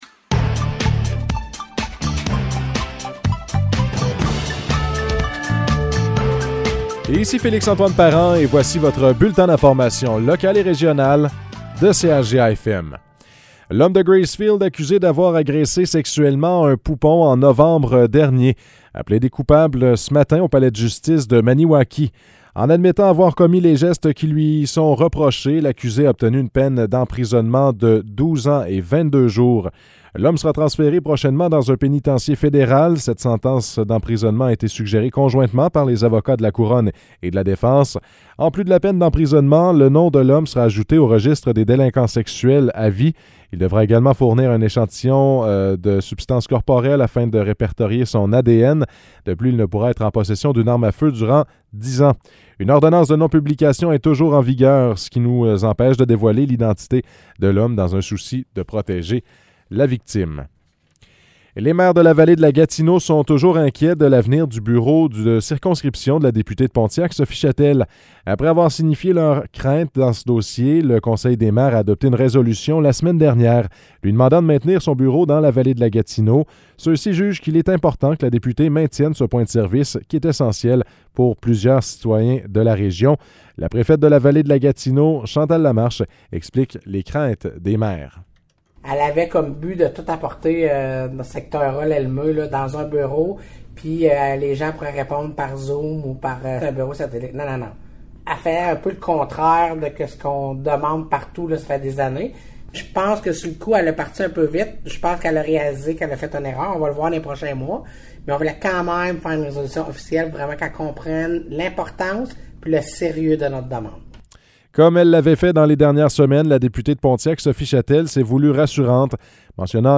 Nouvelles locales - 21 mars 2022 - 12 h